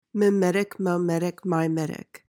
PRONUNCIATION:
(mi/muh/my-MET-ik)